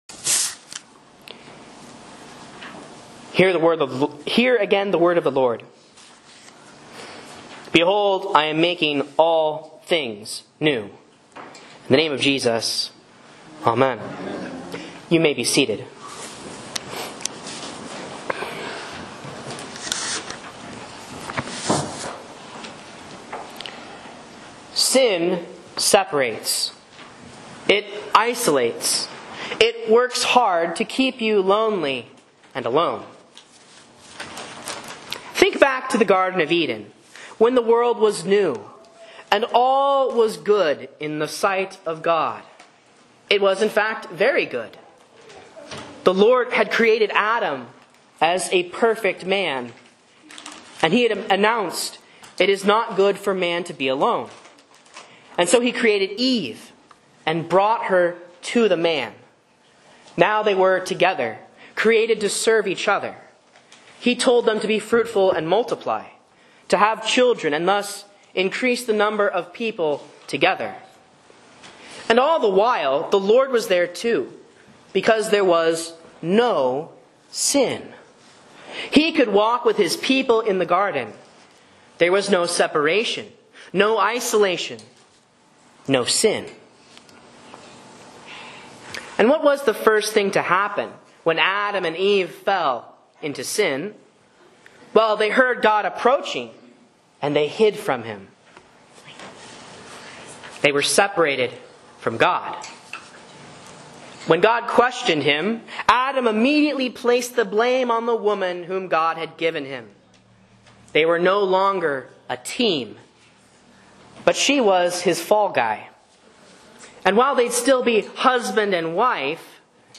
Sermons and Lessons from Faith Lutheran Church, Rogue River, OR
A Sermon on Revelation 21:5 for Easter 5 (C)